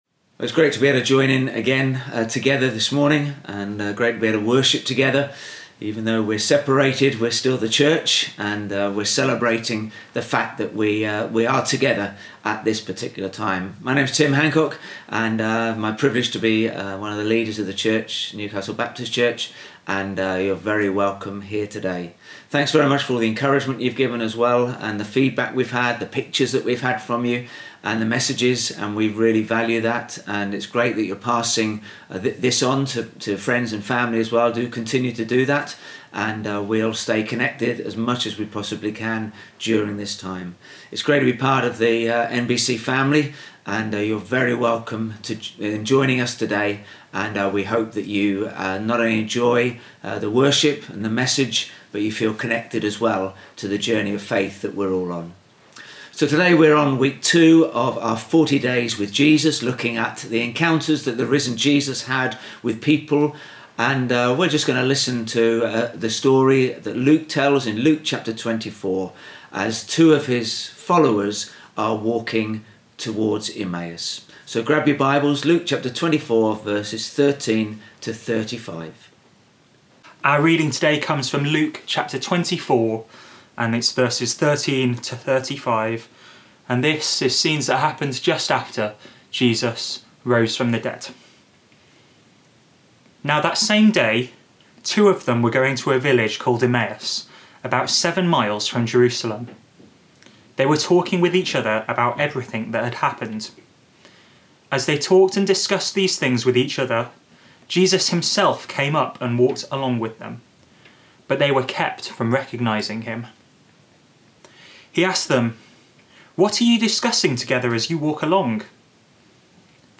Sunday-19th-April-Service.mp3